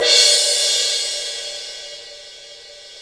Index of /90_sSampleCDs/Roland L-CD701/CYM_Crashes 1/CYM_Crsh Modules
CYM MALLET0D.wav